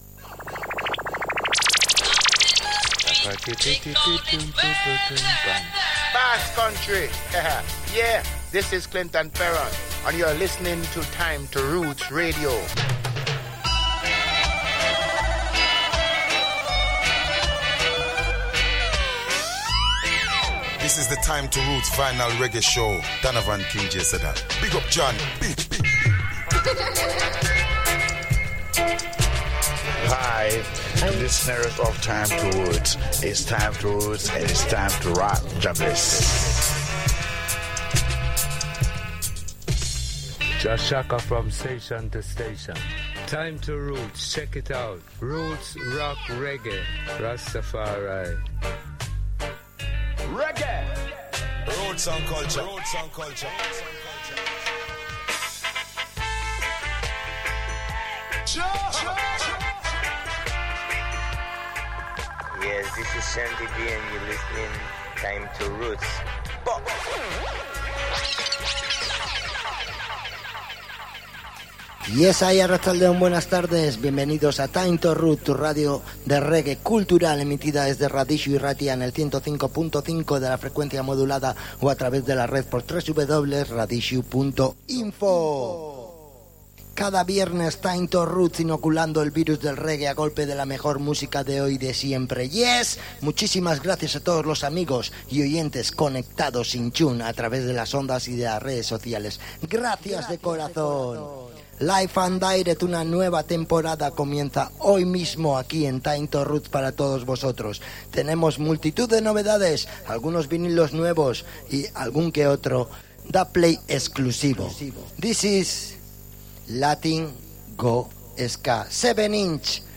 New Vinyls, New Releases, Pre-Releases, and Dub Plates
a new 100% Dub album on 12" vinyl at 45 RPM.